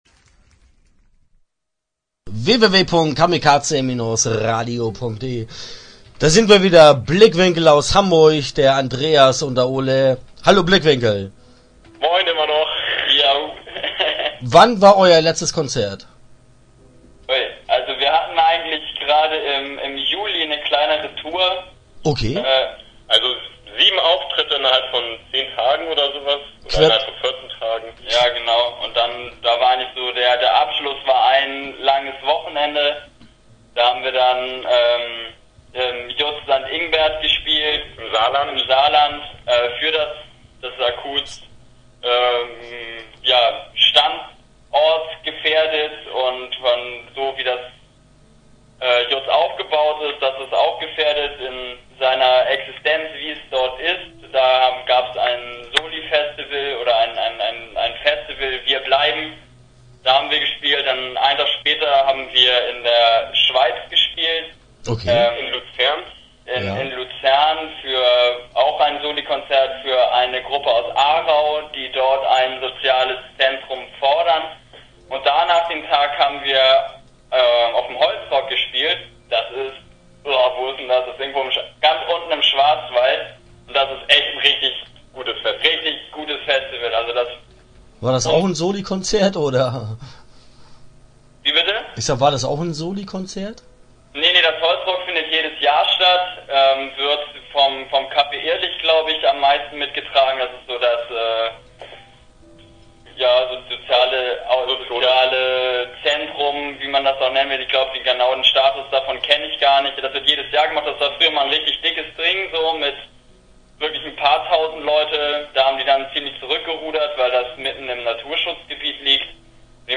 Interview Teil 1 (10:52)